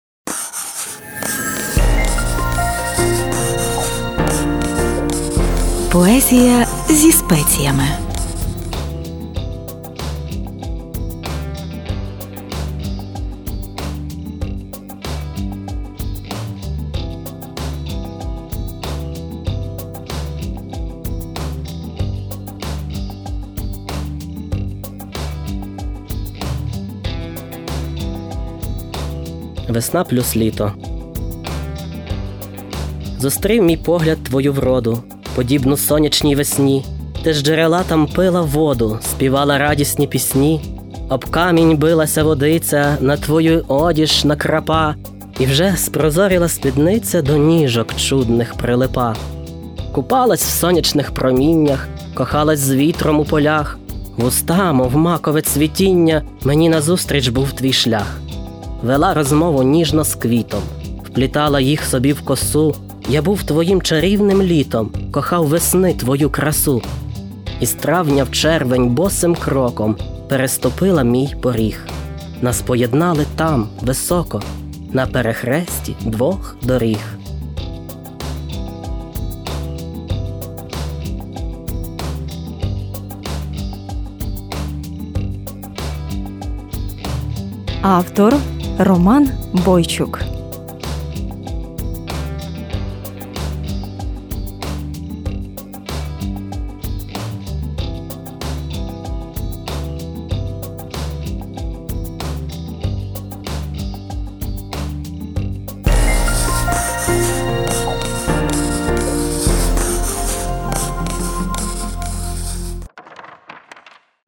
Оце я тепер вирішив подовиставляти все, що вже з радіо прозвучало)